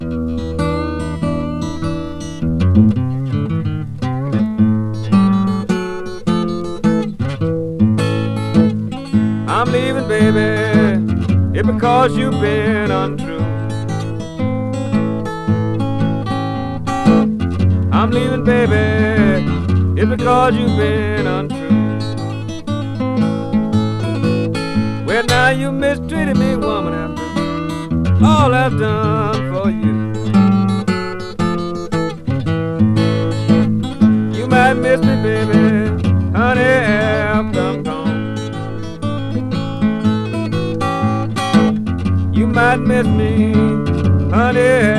Blues, Country Blues, Ragtime　USA　12inchレコード　33rpm　Stereo